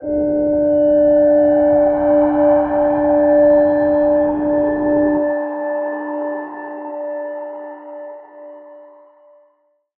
G_Crystal-D5-pp.wav